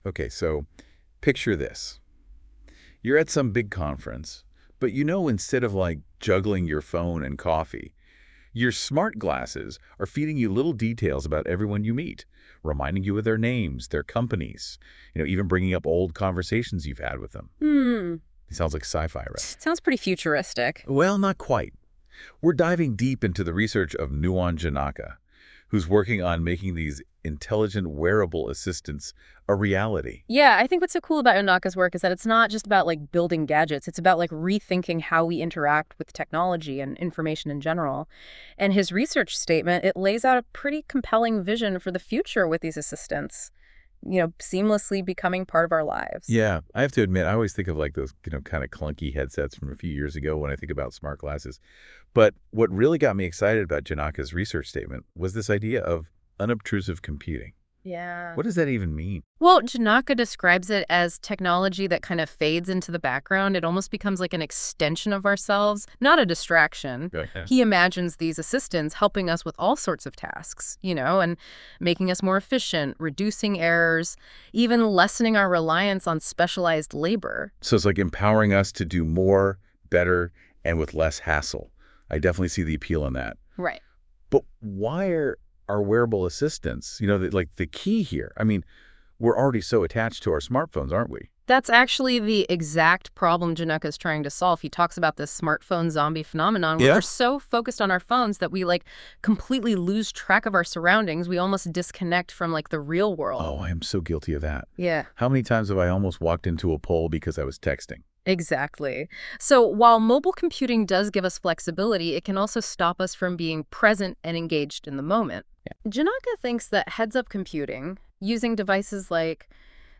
Towards Intelligent Wearable Assistants (UbiComp ’24: Companion) Towards Intelligent Wearable Assistants (generated using Google NotebookLM)